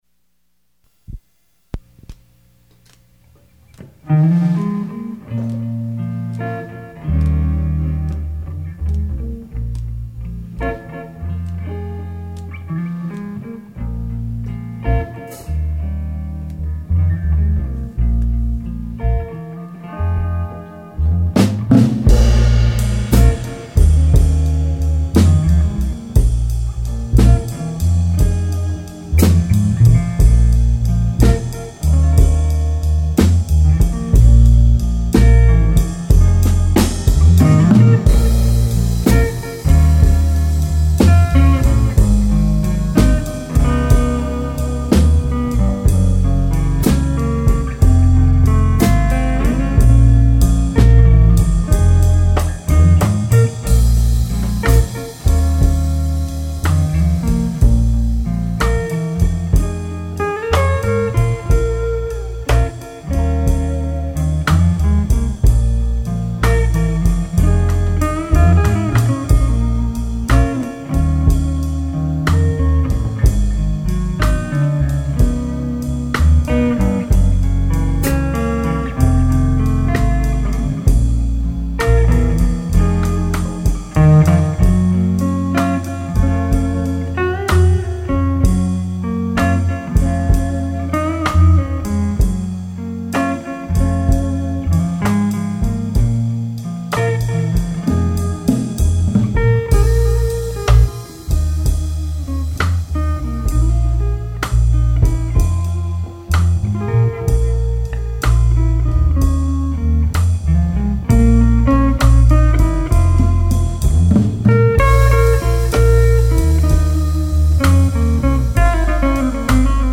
Trio Live